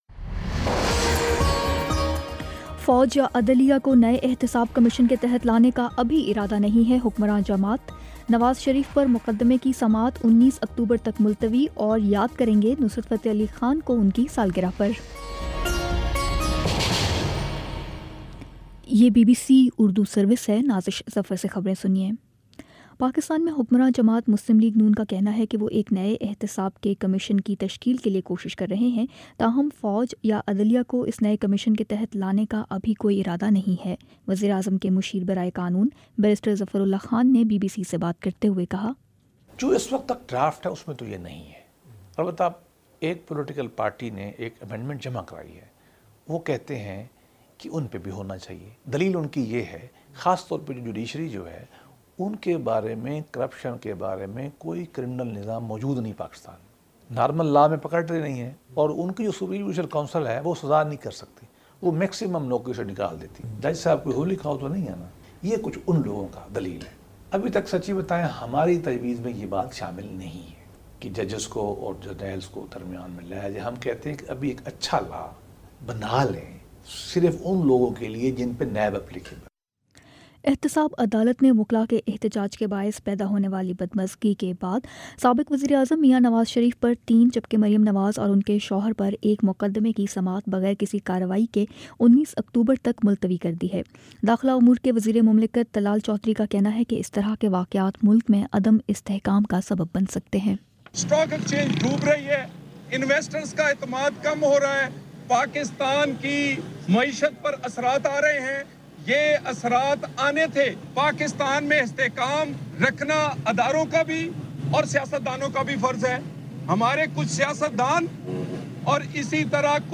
اکتوبر 13 : شام سات بجے کا نیوز بُلیٹن